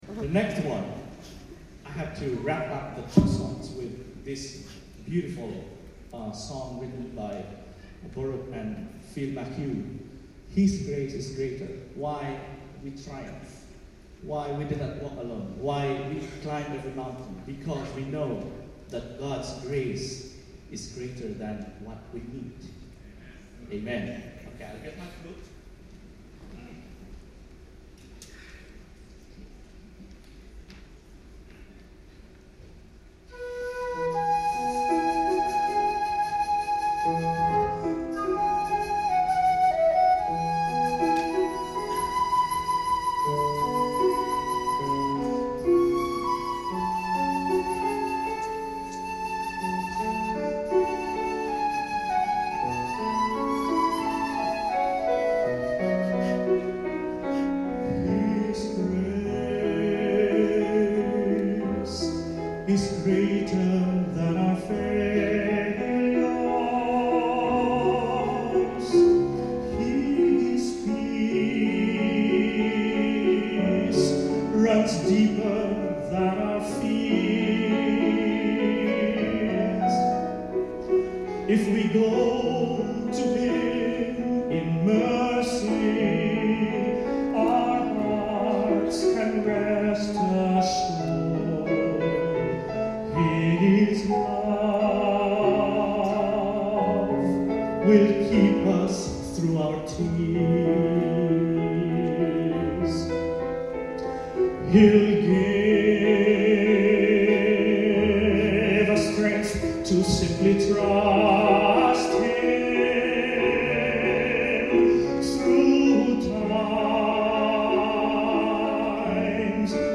I took the opportunity to test out the in-ear binaurals I got from The Sound Professionals and borrowed an old MiniDisc player.
These songs are best heard on headphones to hear the direction of chatter, laughter and so on.